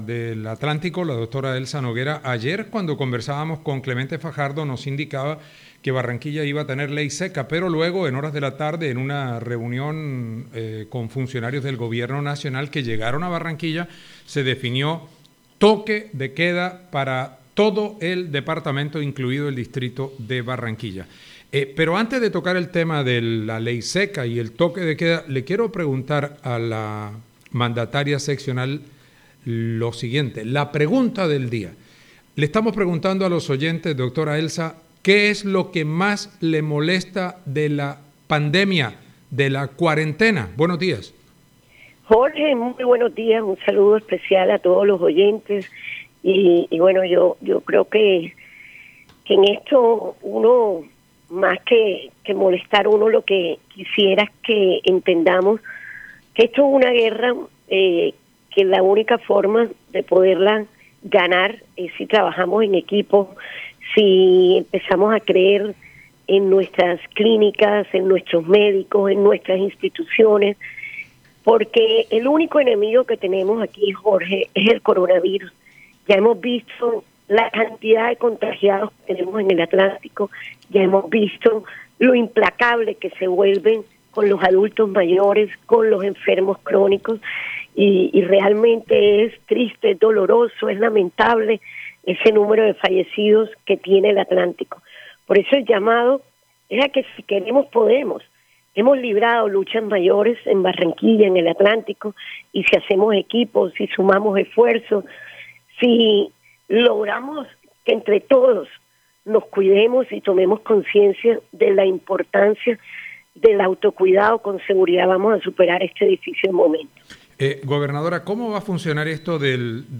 La gobernadora del Atlántico, Elsa Noguera, justificó la implementación del Toque de Queda y la Ley Seca en los 22 municipios del departamento y en Barranquilla.